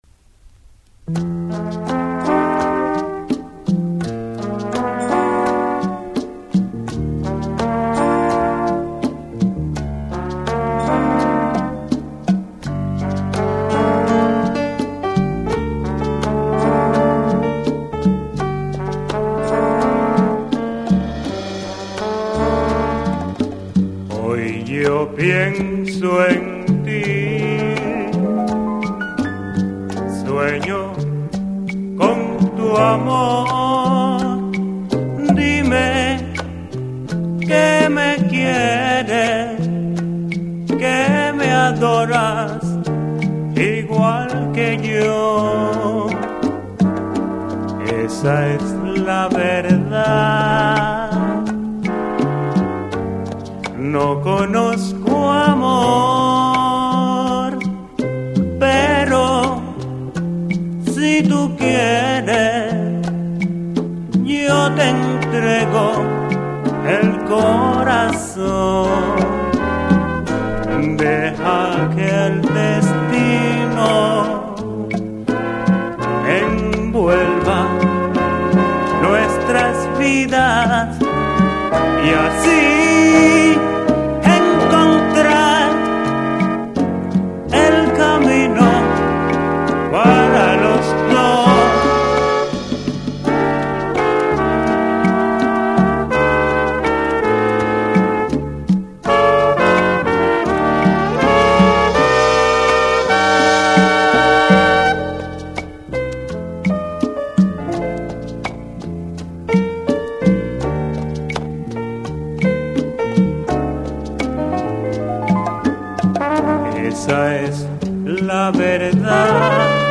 Wir bieten Ihnen hier eine kleine Auswahl an frei verfügbarer kubanischer Musik.